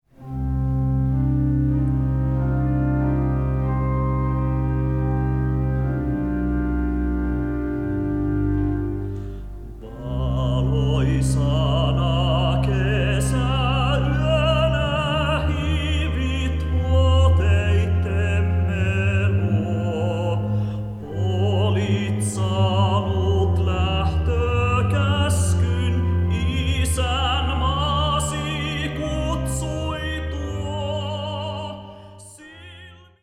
Urkuäänitykset: Karjasillan kirkko
Pianoäänitykset: Thulinbergin sali